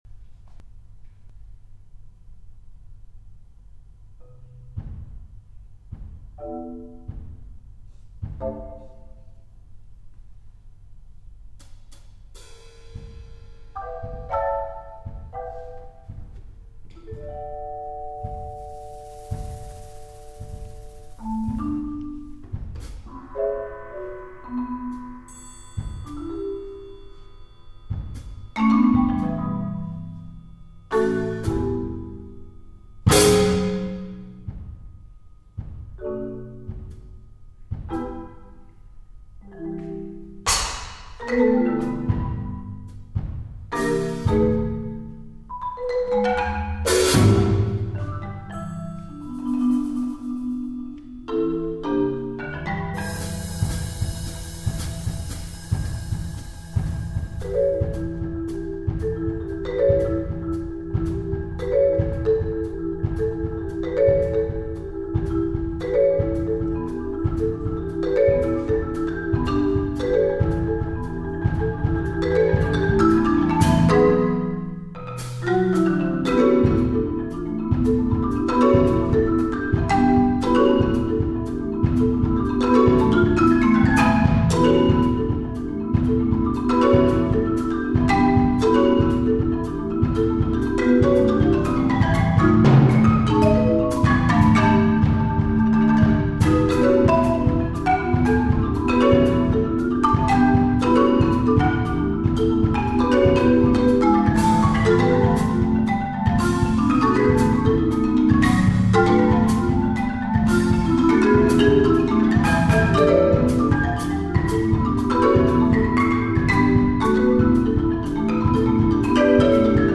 percussion duo